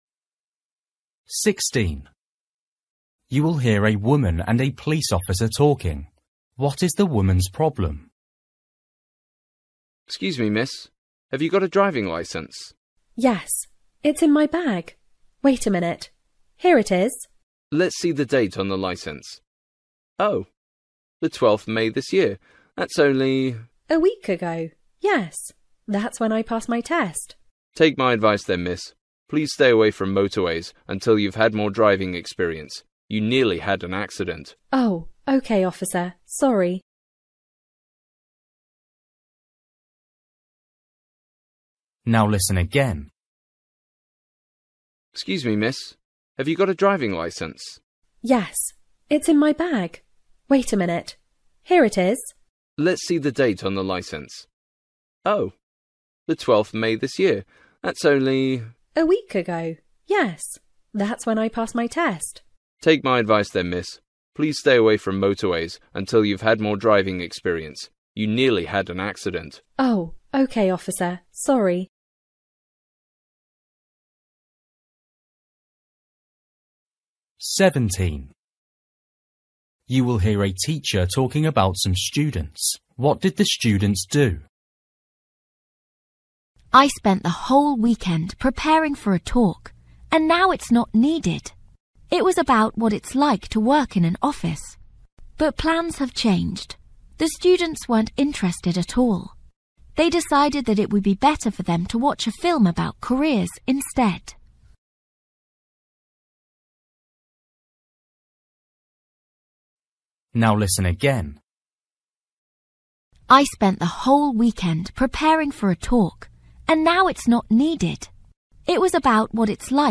Listening: everyday short conversations
16   You will hear a woman and a police officer talking. What is the woman’s problem?
17   You will hear a teacher talking about some students. What did the students do?
20   You will hear two friends talking. Where are they?